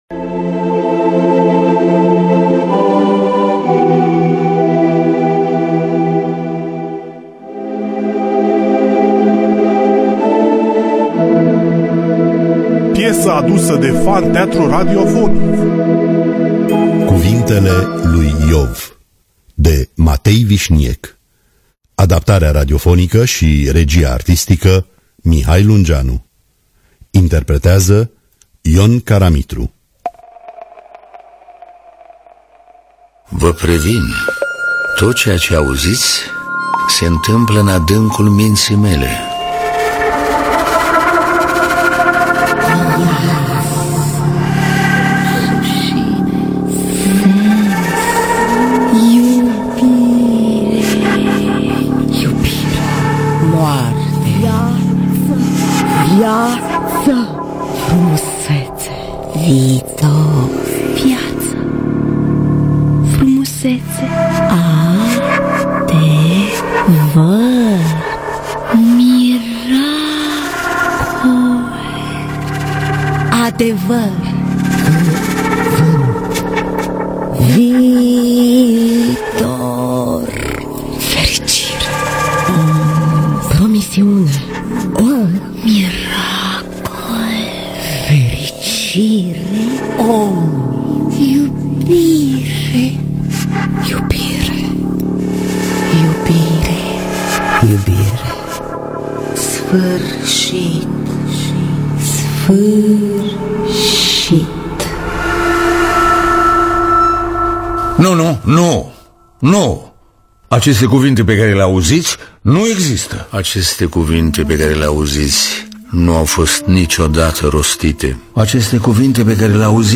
Cuvintele lui Iov de Matei Vișniec – Teatru Radiofonic Online